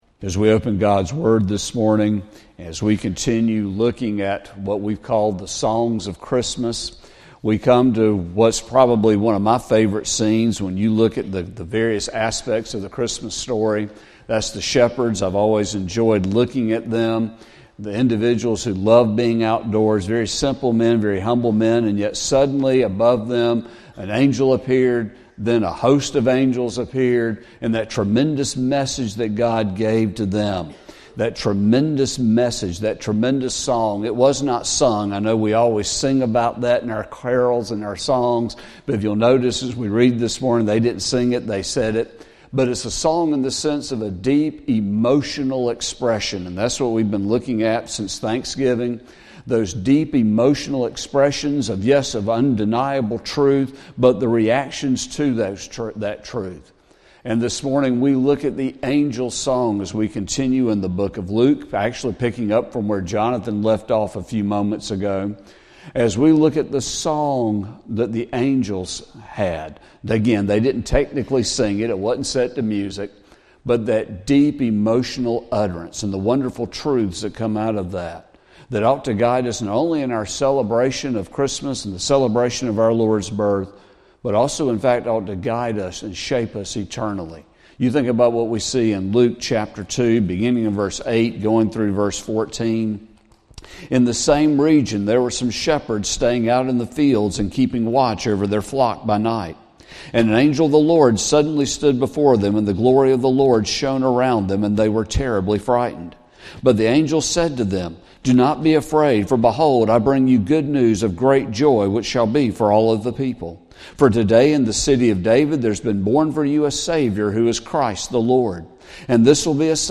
Sermon | December 22, 2024